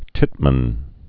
(tĭtmən)